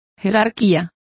Complete with pronunciation of the translation of seniority.